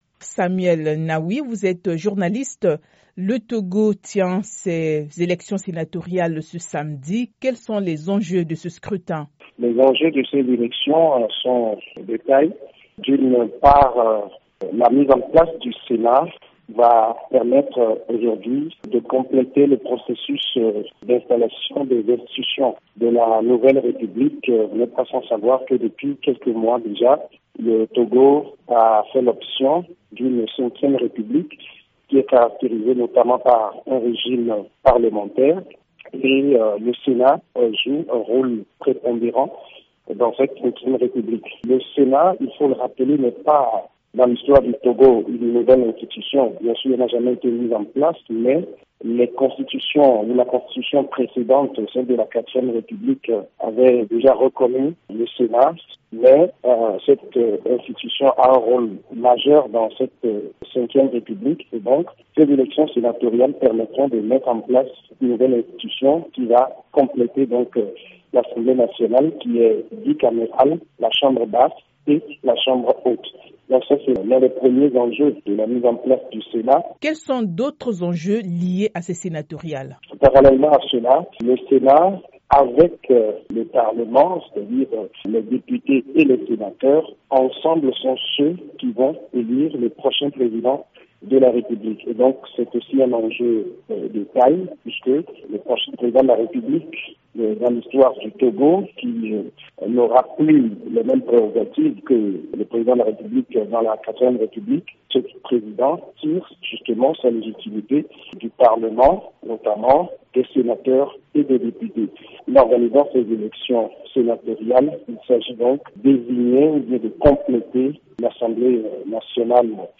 Les enjeux des élections sénatoriales au Togo : Une interview